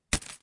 揉纸/扔纸 " 扔纸篓2
描述：我把一张纸扔进垃圾桶（金属）。 以Zoom Q4 WAV格式录制。
Tag: 弄皱 滨折腾 报纸 起皱 弄皱 起皱 折腾 沙沙 沙沙 纸折腾 纸在斌 压皱